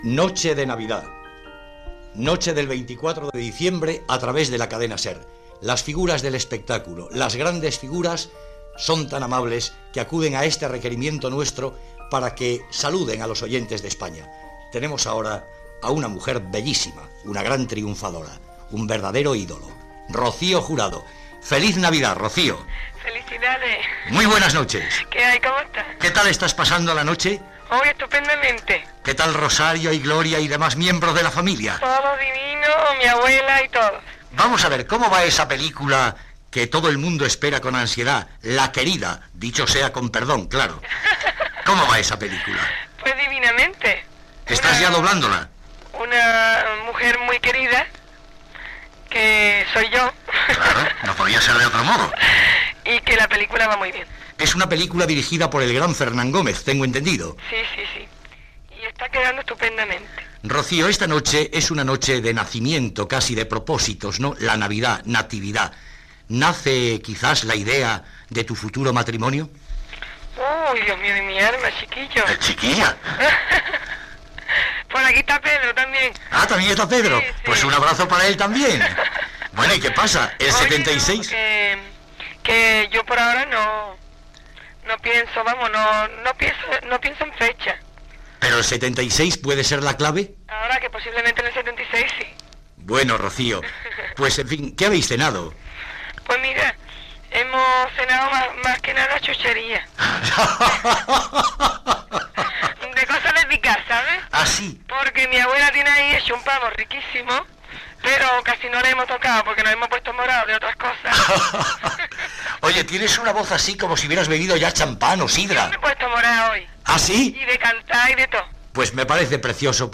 Identificació del programa, entrevista a la cantant Rocío Jurado sobre com passa la nit de Nadal, la pel·lícula que està rodant i el seu possible matrimoni.